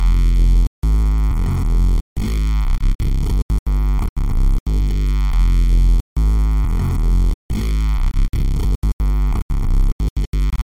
Tag: 90 bpm Drum And Bass Loops Synth Loops 1.79 MB wav Key : Unknown